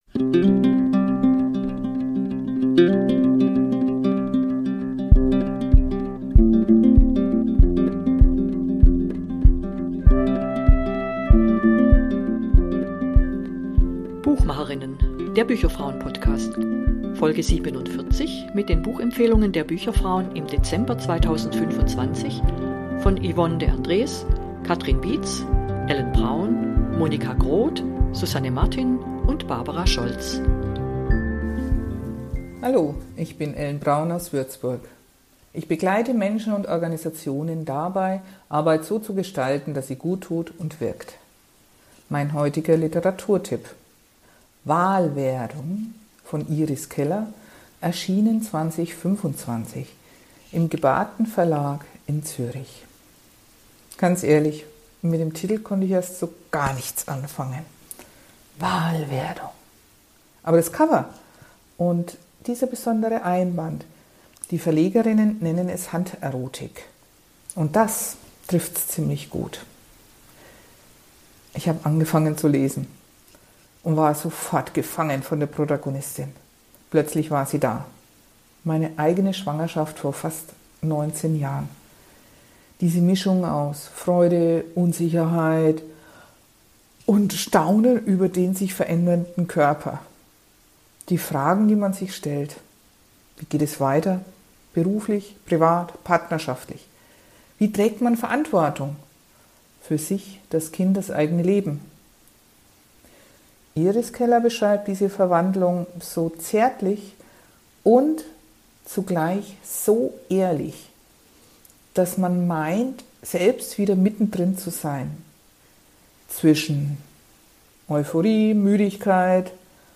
Sechs BücherFrauen aus ganz Deutschland haben per Sprachnachricht ihre Beiträge gesendet.